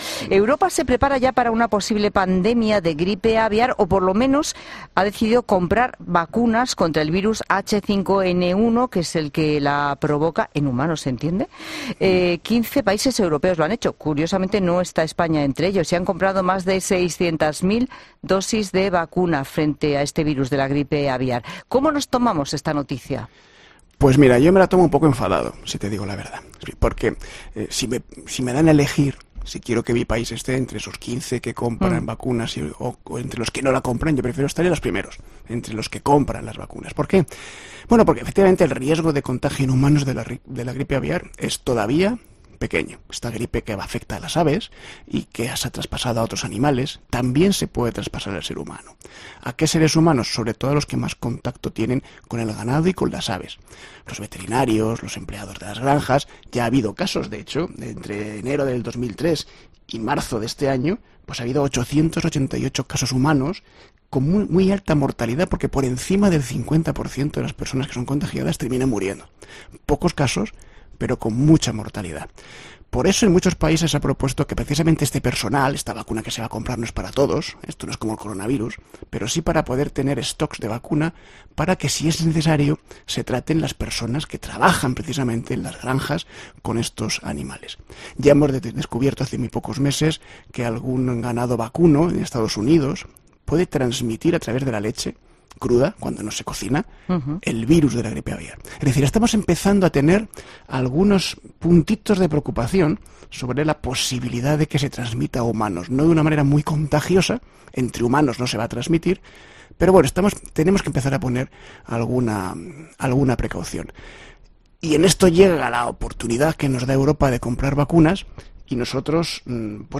divulgador científico, explica en COPE cuál es el verdadero riesgo de contagio de este virus en humanos y si debe o no preocuparnos en España